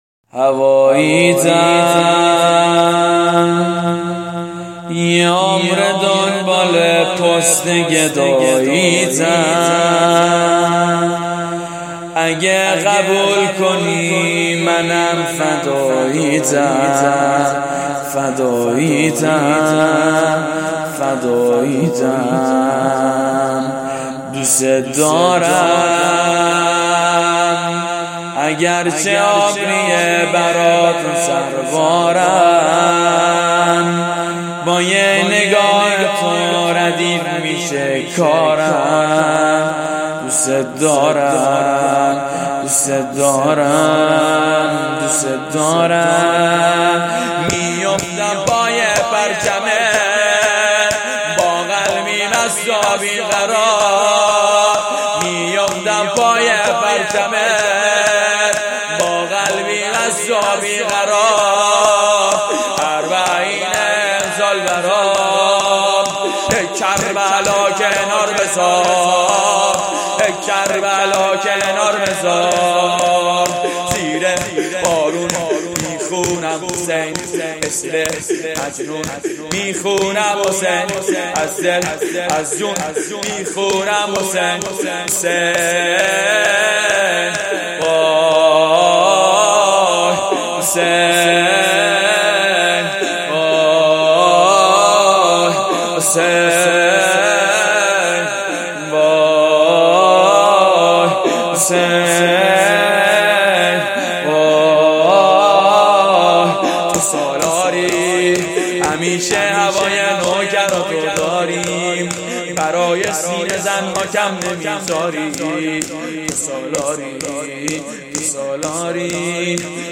شور بسیار احساسی هواییتم یه عمره دنبال پست گداییتم